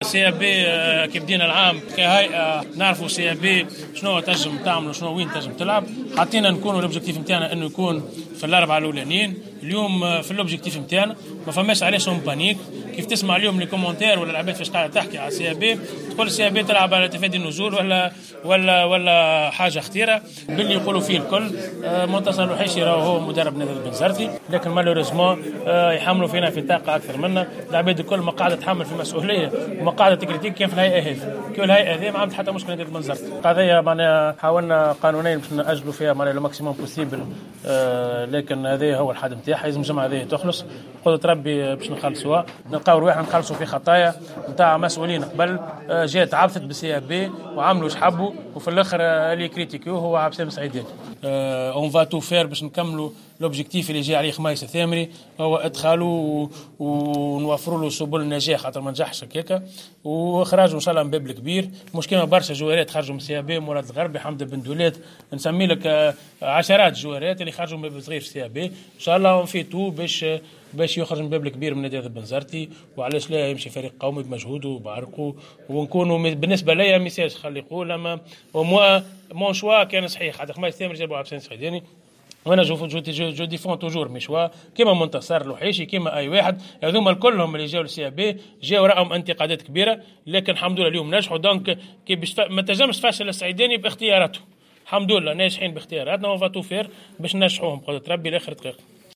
عقد النادي البنزرتي ندوة صحفية اليوم الثلاثاء 16 أفريل 2019 ندوة صحفية لتوضيح عدد...